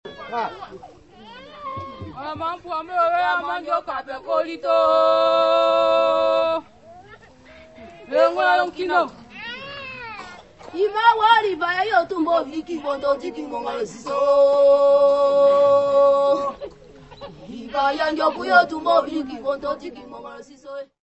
: stereo; 12 cm + folheto
Recolha e gravações
Área:  Tradições Nacionais